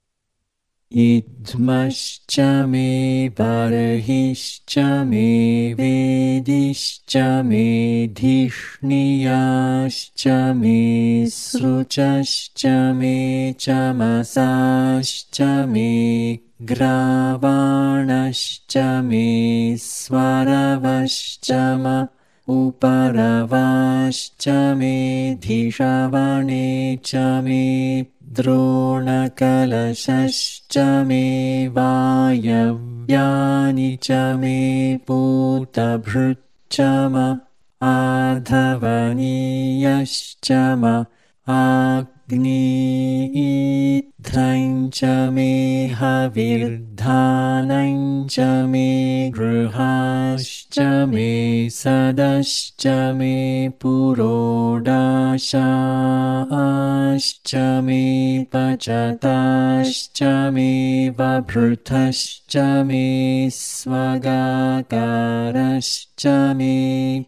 Some tools to learn and chant the Vedas:
Pdf (text files, mantras in dēvanāgarī and RCCS), Mp3 (audios, moderate speed)
cham8_chant.mp3